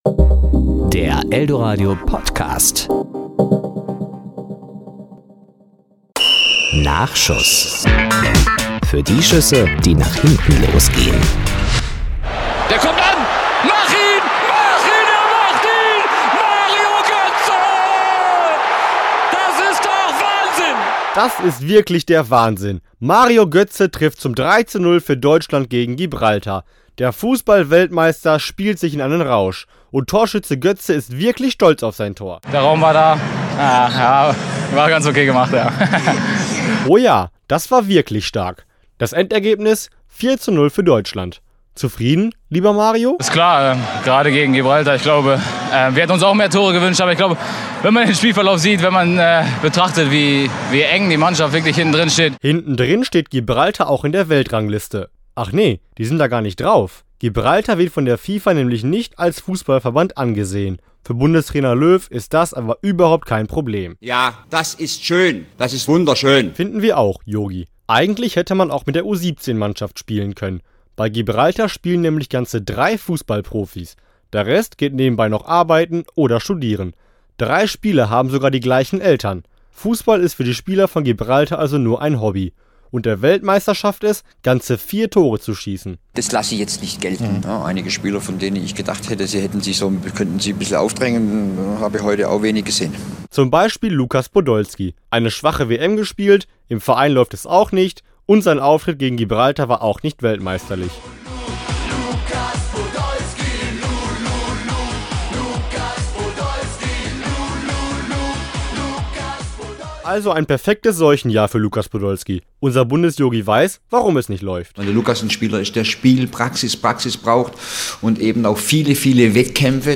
Serie: Wort